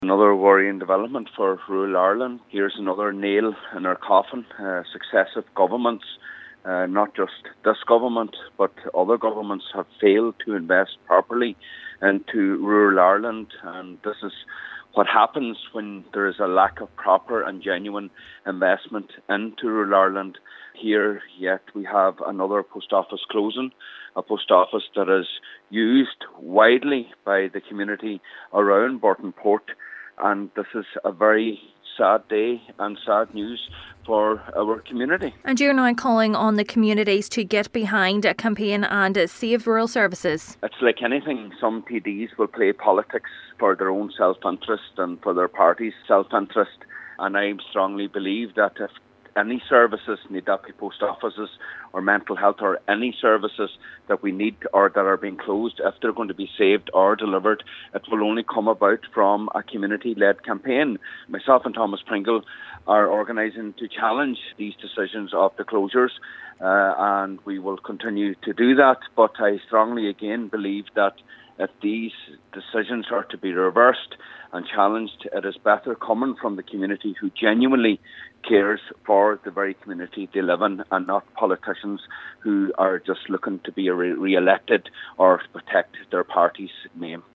Councillor Micheal Cholm MacGiolla Easbuig says the lack of investment in rural Ireland is having a devastating effect on communities: